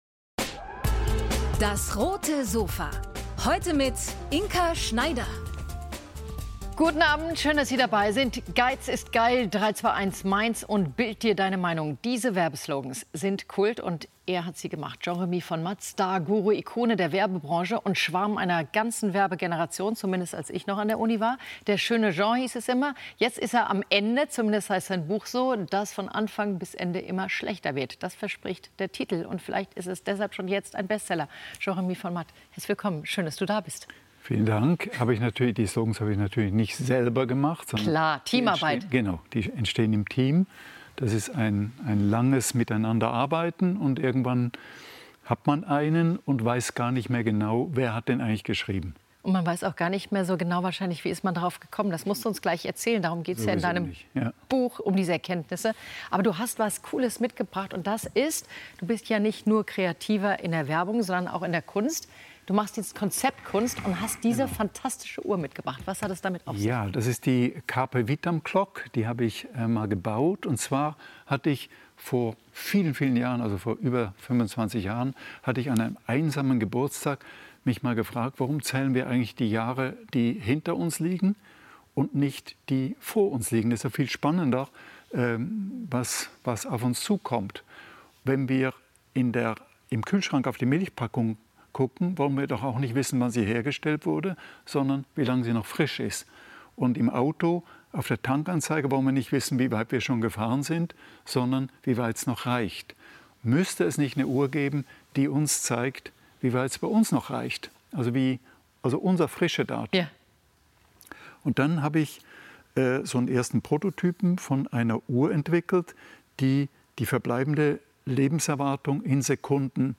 Autor und Werber Jean Remy von Matt über Kreativität ~ DAS! - täglich ein Interview Podcast